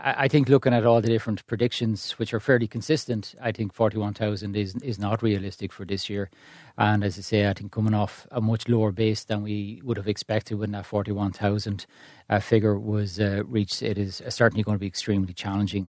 Minister James Browne admits the government’s target is not going to be reached………